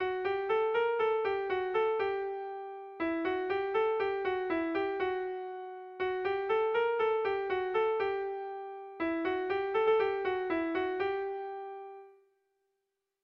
Sehaskakoa
ABAB